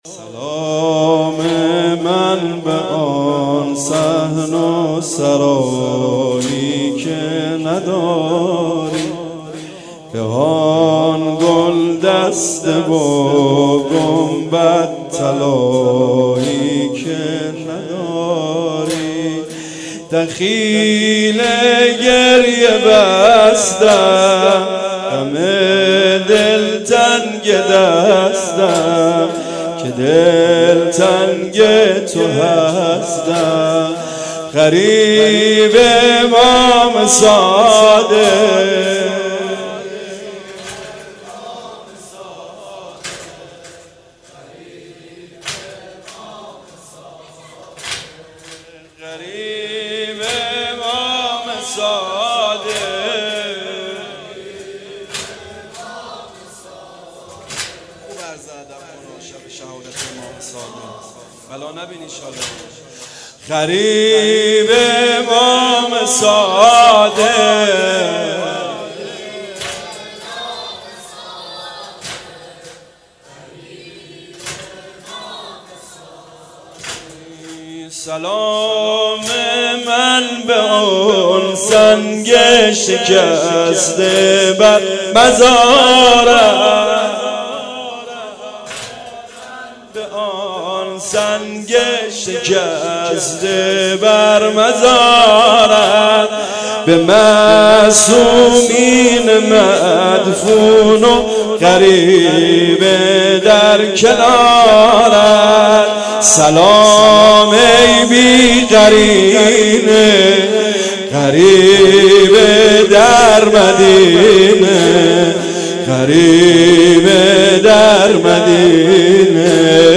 شهادت امام صادق علیه السلام-شب سوم